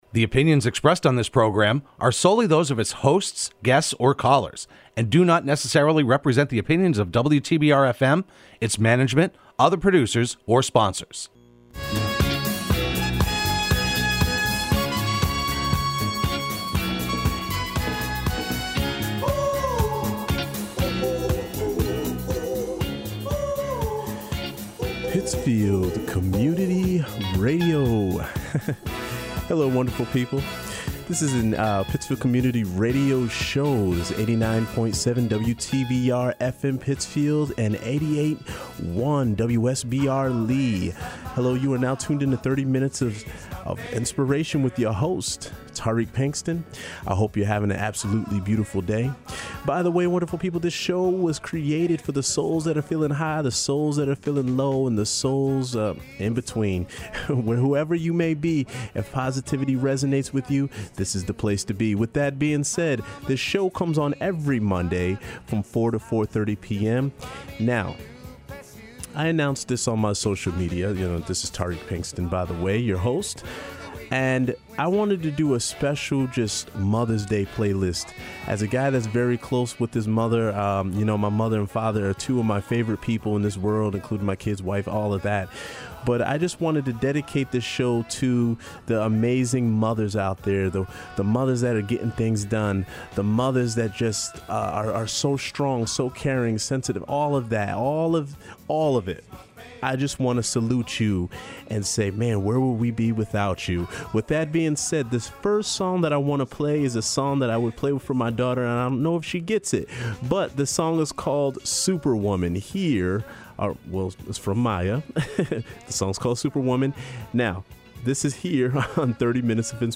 broadcast live every Monday afternoon at 4pm on WTBR